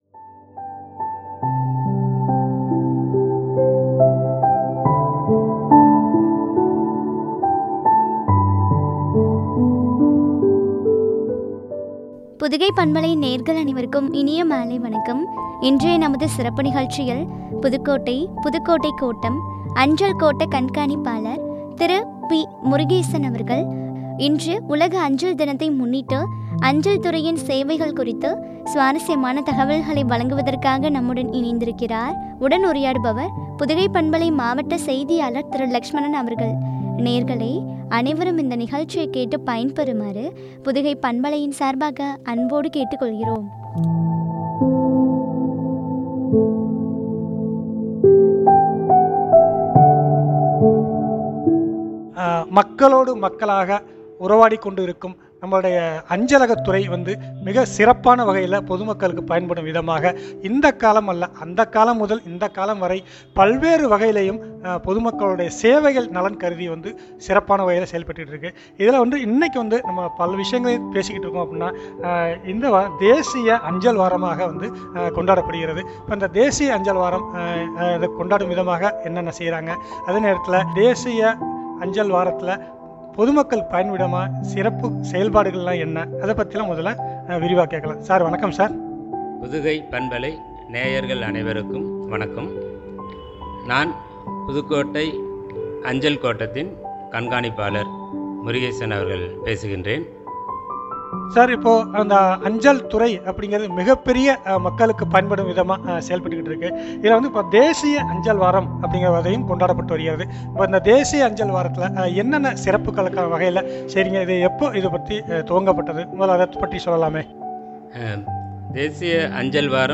“அஞ்சல் துறையின் சேவைகள்” குறித்து வழங்கிய உரையாடல்.